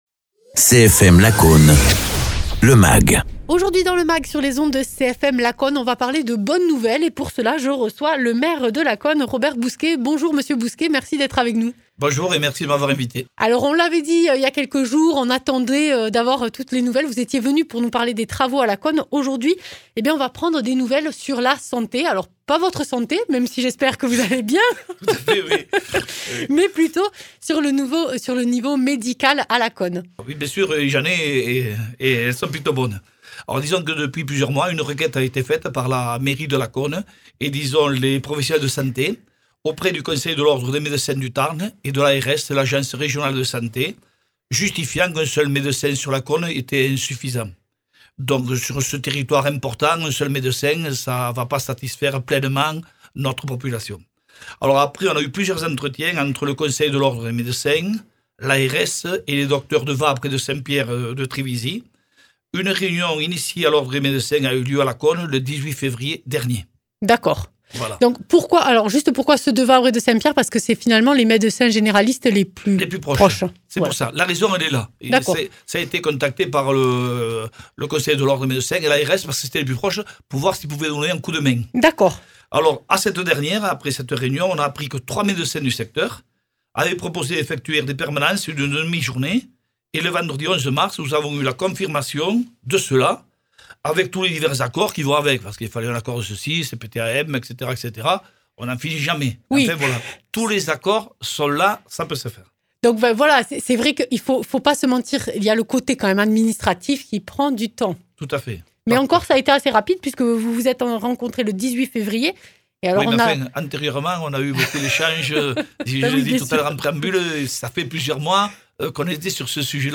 Interviews
Invité(s) : Robert Bousquet, maire de Lacaune-les-bains (Tarn)